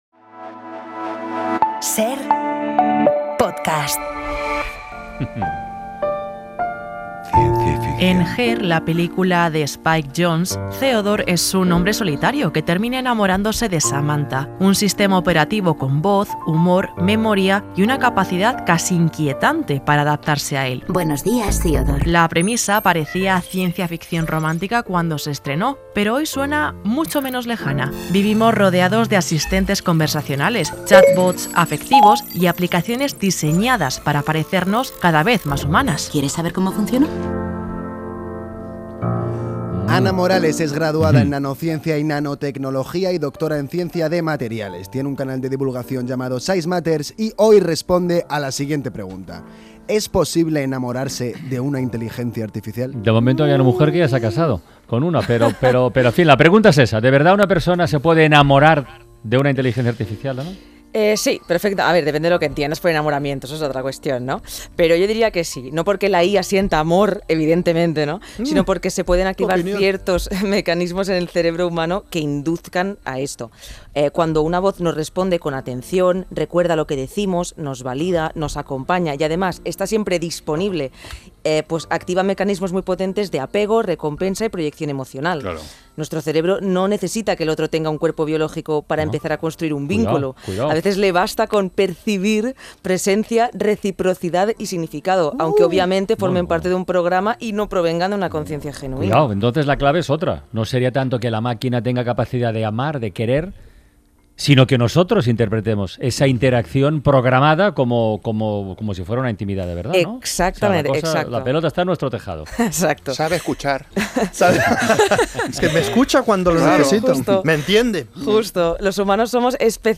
La nanotecnóloga y divulgadora